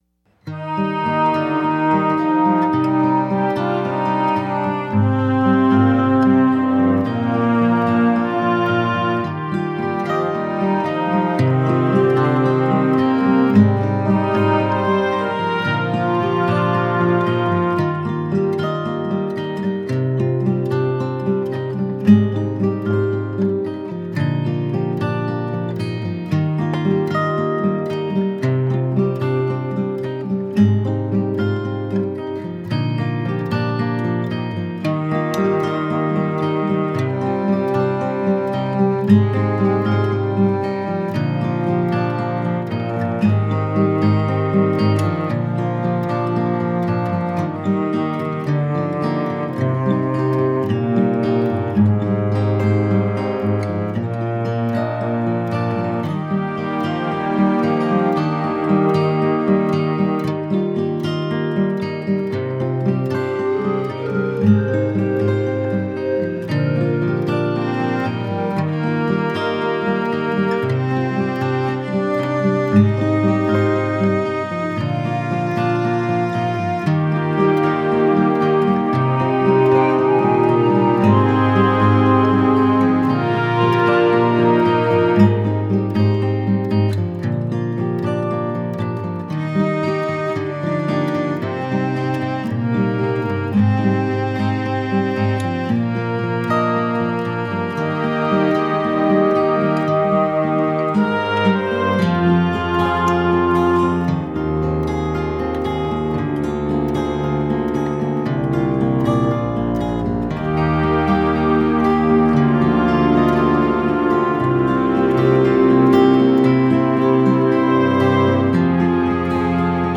youre-not-the-one-karaoke.mp3